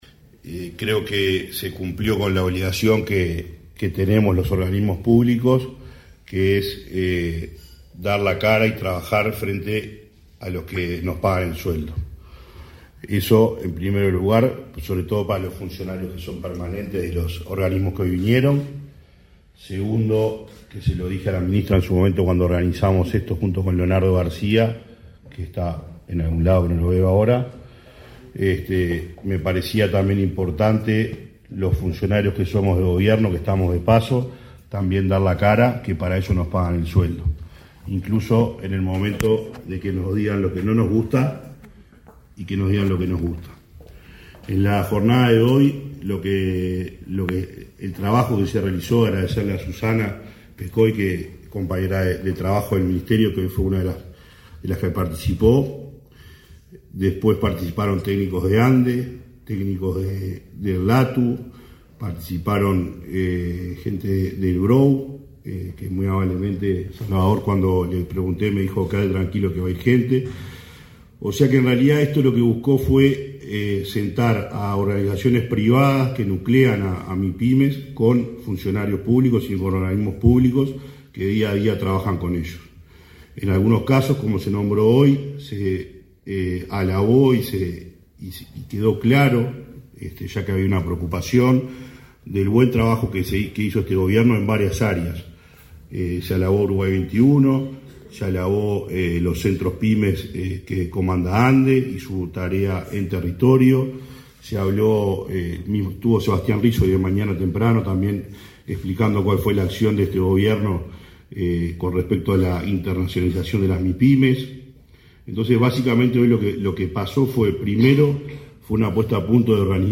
Foro organizado por el MIEM sobre promoción de las Mipymes 21/08/2024 Compartir Facebook X Copiar enlace WhatsApp LinkedIn El Ministerio de Industria, Energía y Minería (MIEM) organizó un foro, este 21 de agosto, sobre la promoción de las micro, pequeñas y medianas empresas (mipymes) orientado a la competitividad empresarial. Participaron la ministra, Elisa Facio; el presidente del Banco República, Salvador Ferrer, y el director nacional de Artesanías, Pequeñas y Medianas Empresas del MIEM, Gonzalo Maciel.